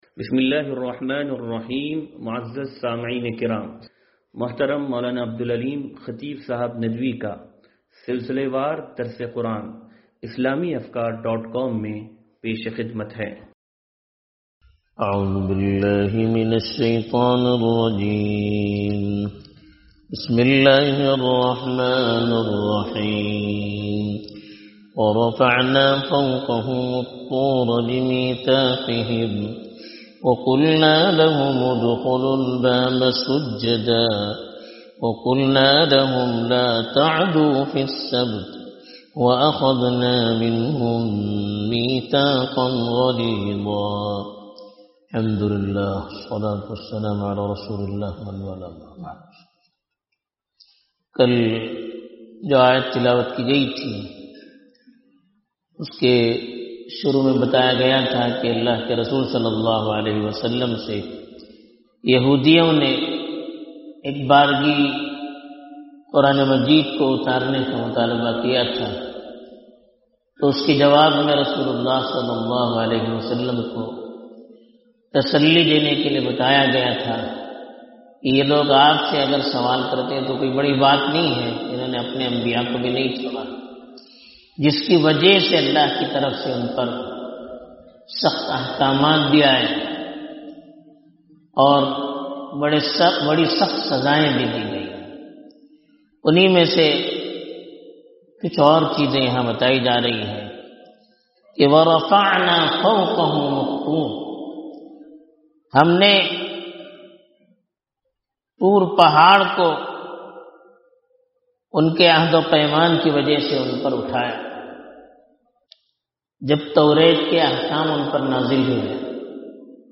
درس قرآن نمبر 0414